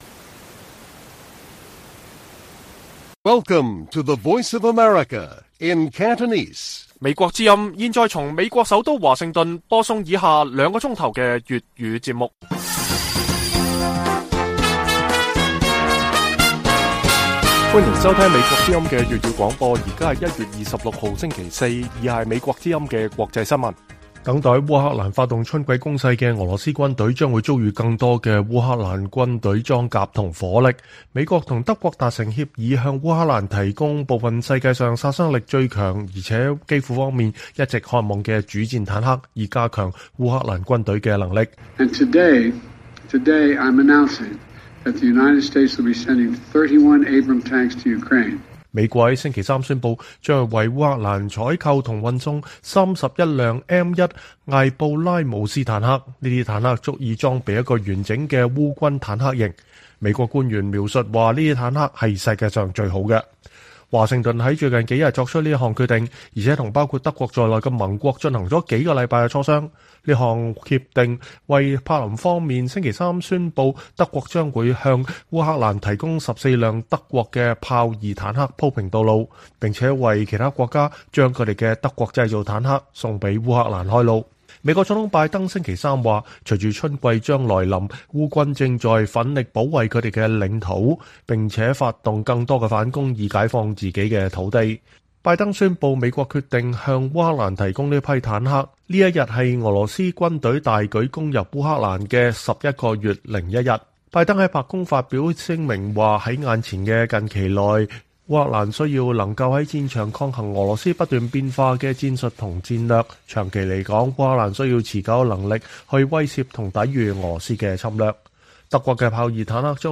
粵語新聞 晚上9-10點：美德將向烏克蘭提供先進坦克